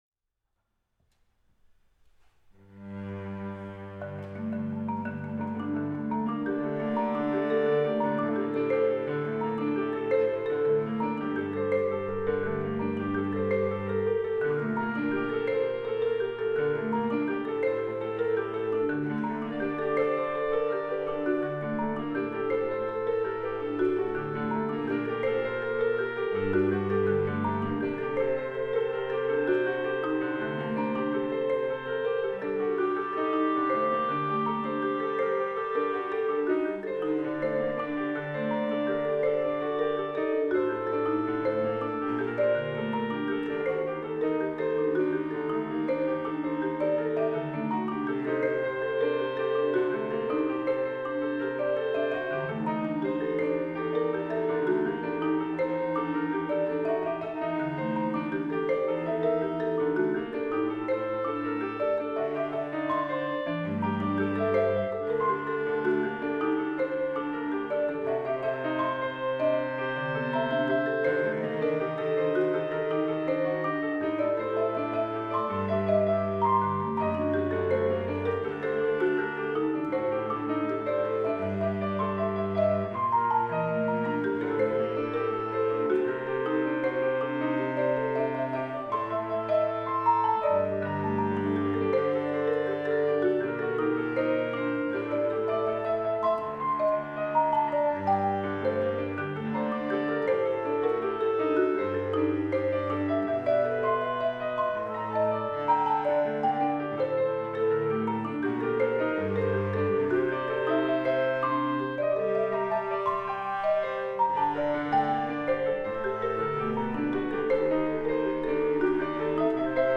aleatoric composition